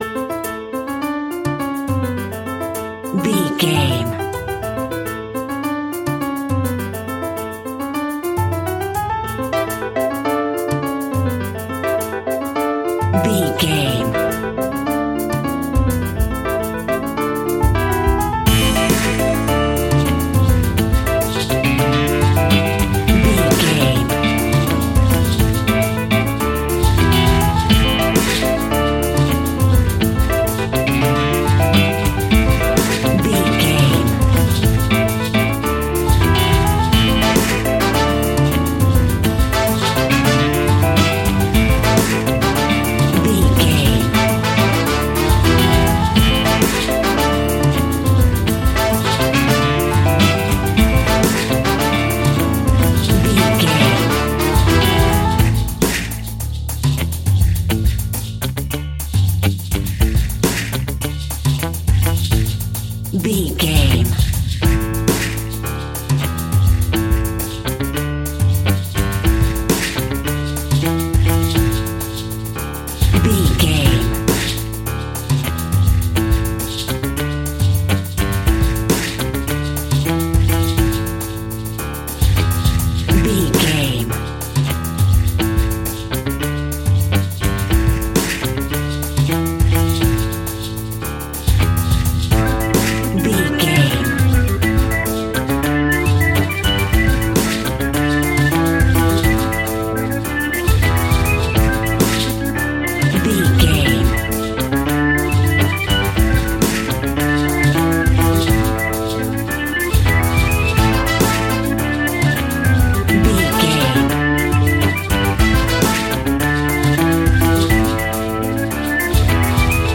Aeolian/Minor
romantic
maracas
percussion spanish guitar